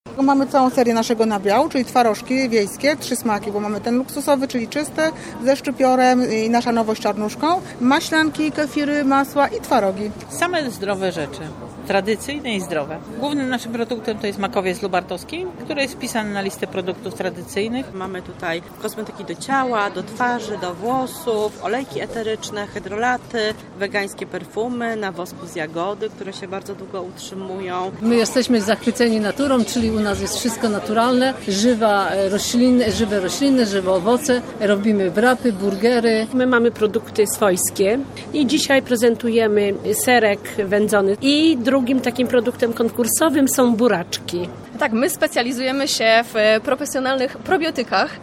Rozpoczął się Wojewódzki Konkurs Produktów Tradycyjnych “Tygiel Smaków 2022”. Konkurs trwa podczas tegorocznych Targów Żywności Ekologicznej i Naturalnej w Atlas Arenie w Łodzi.
O tym co można znaleźć na stoiskach mówią wystawcy.